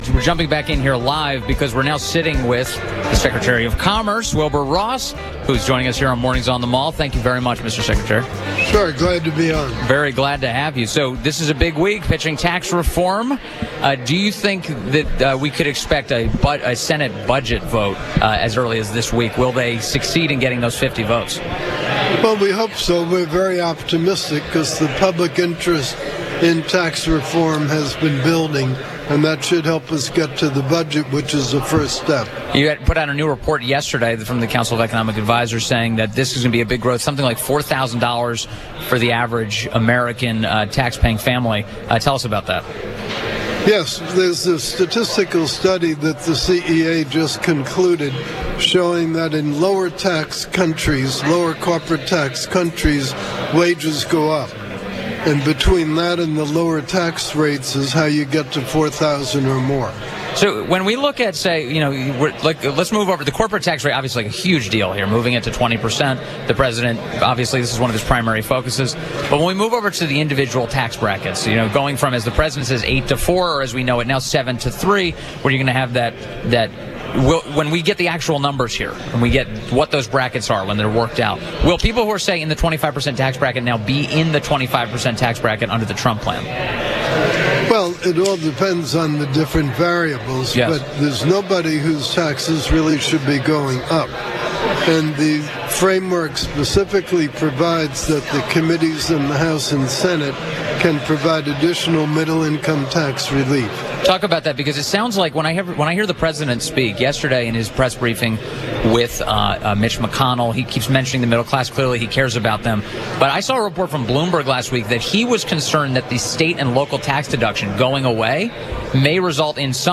WMAL Interview - COMMERCE SECRETARY WILBUR ROSS - 10.17.17